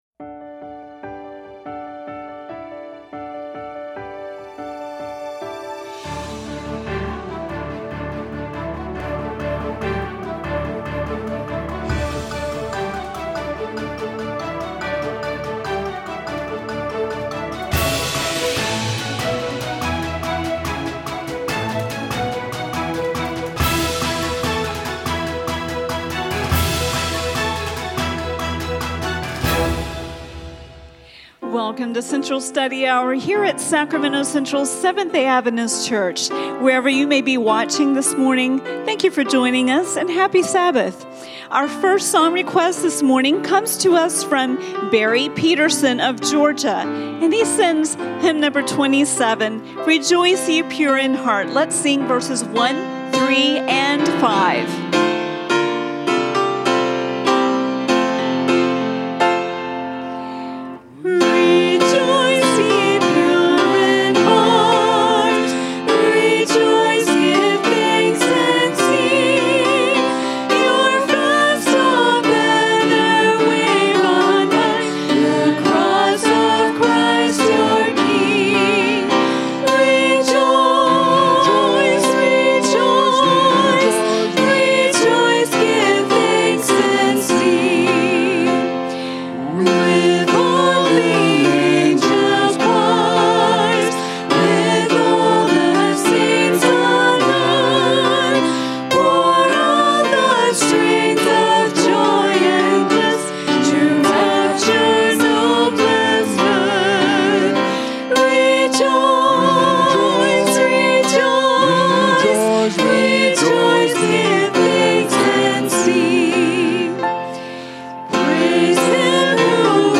A message from the series "Making Friends For God."